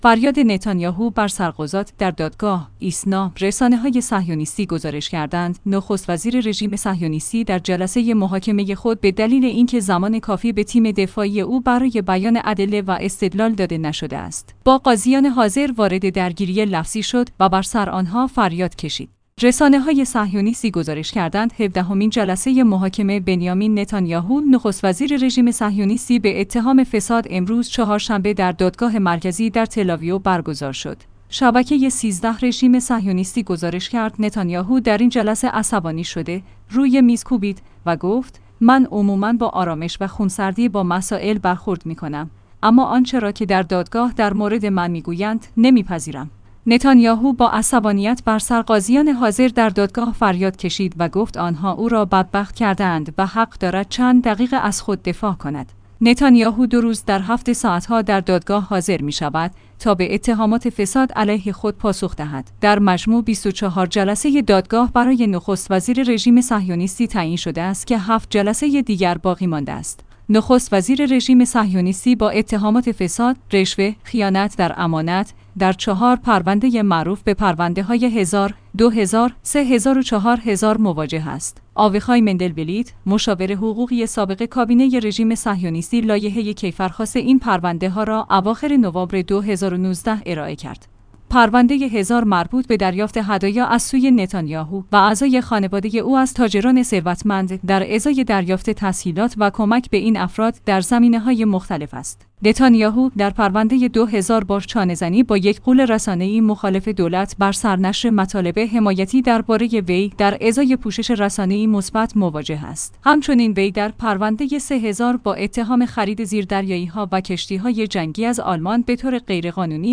فریاد نتانیاهو در دادگاه: شما بدبختم کردید